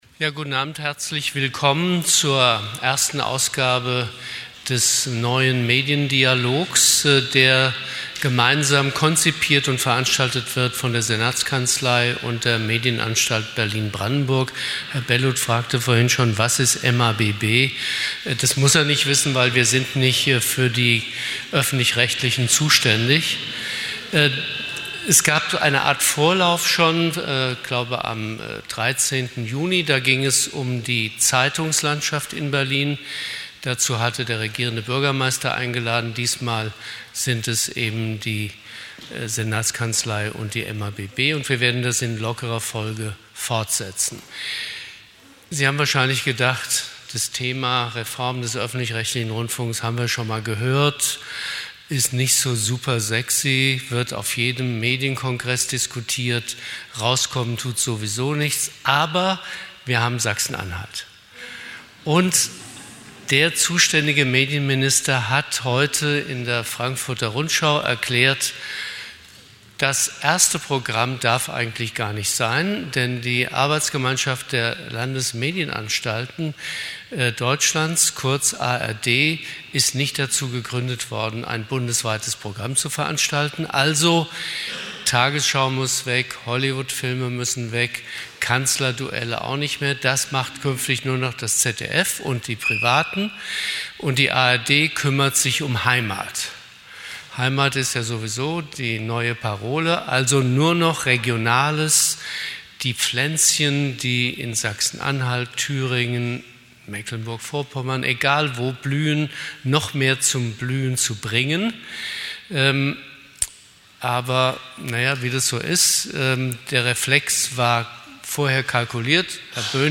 Was: Eröffnungsworte
Wo: Berliner Rotes Rathaus, Wappensaal, Rathausstraße 15, 10178 Berlin